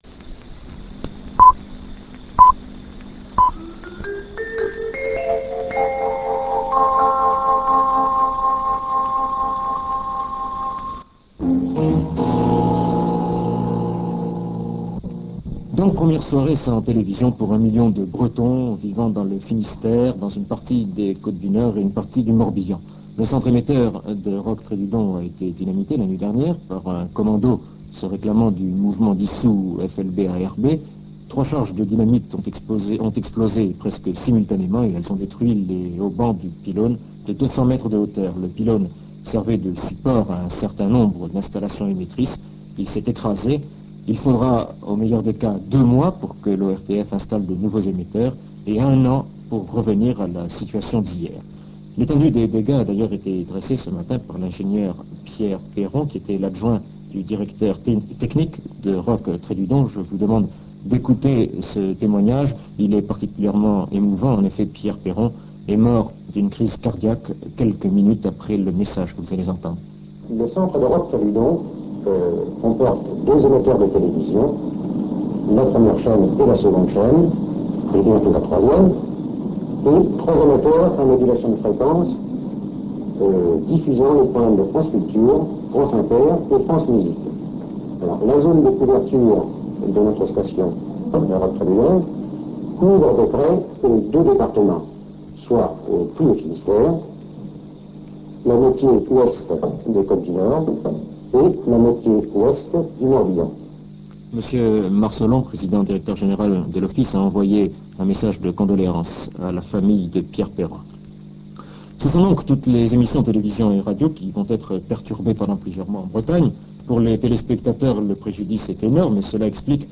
En février 1974 un attentat détruit le site. Journal de France Inter du 14 février 1974 Ecoute directe - Ecoute différée 520 ko